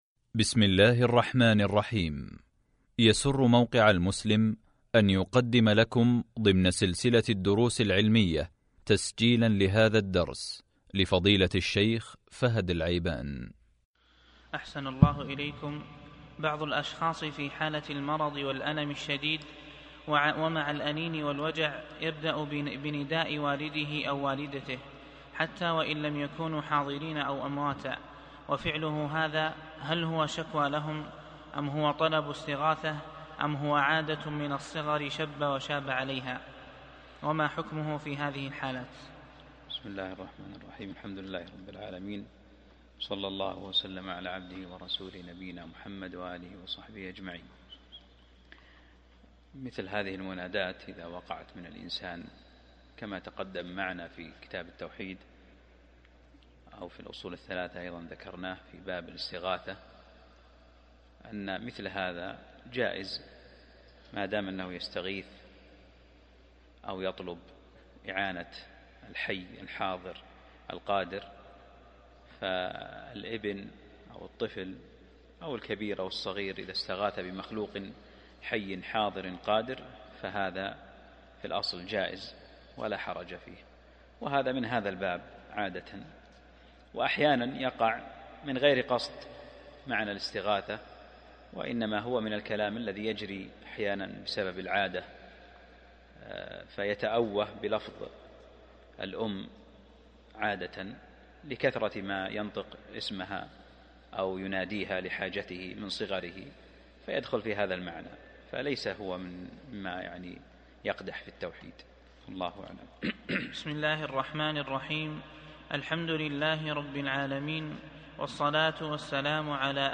الدرس (39) من شرح كتاب التوحيد | موقع المسلم